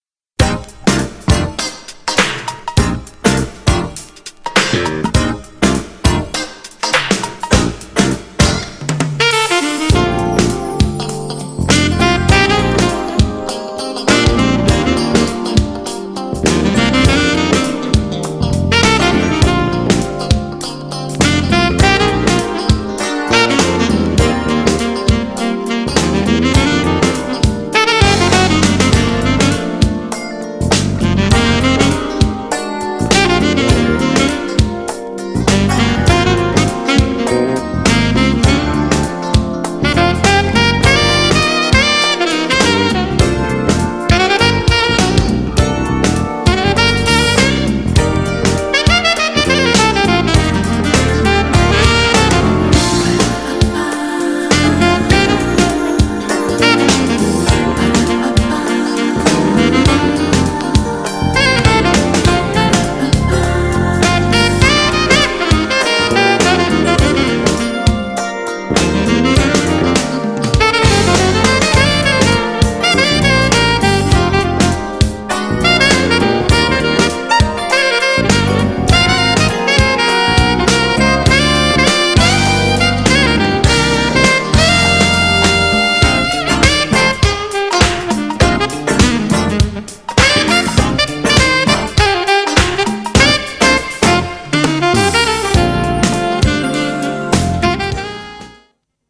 B面は都会的でスインギーなダンスチューン。
GENRE Dance Classic
BPM 61〜65BPM
# INSTRUMENTAL # JAZZY
# SAX # アーバン # カバー曲 # スロー # メロウ